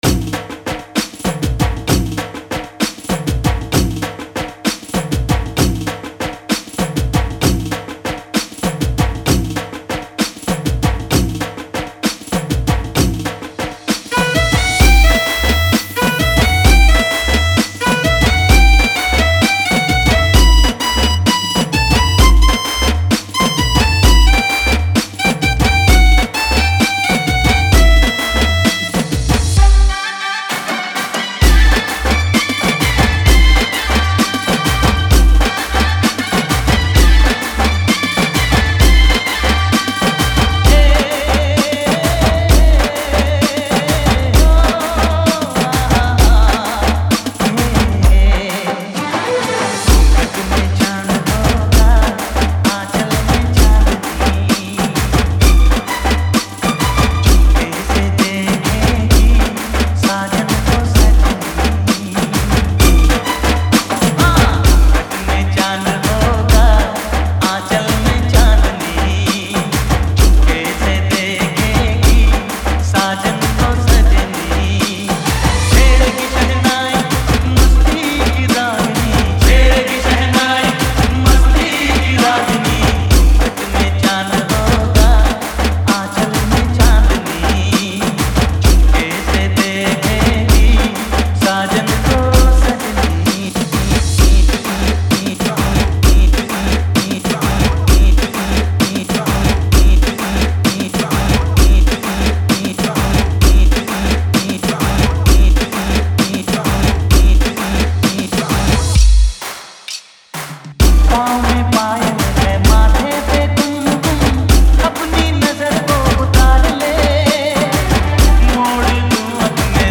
Navratri Dj Remix Song Play Pause Vol + Vol -